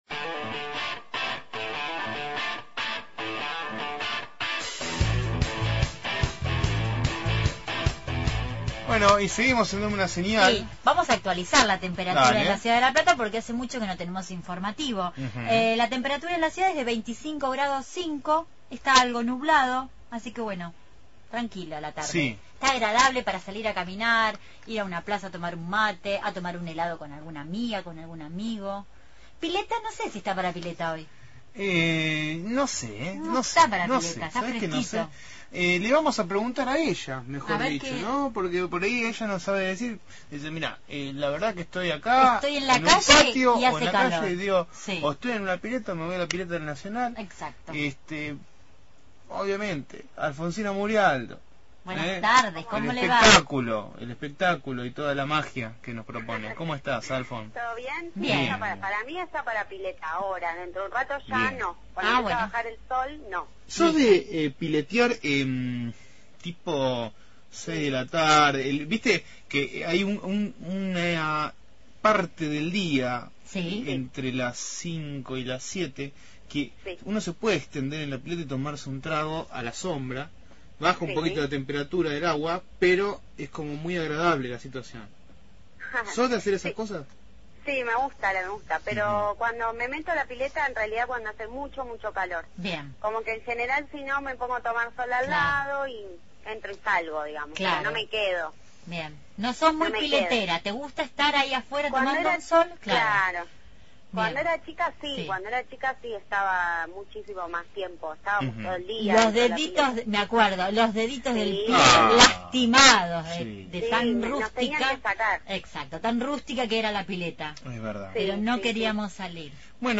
Columna de espectáculos